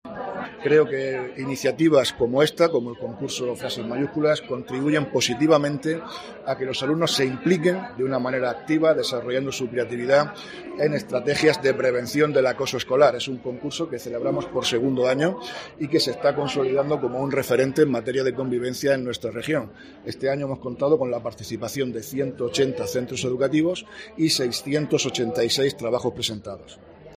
Víctor Marín, consejero de Educación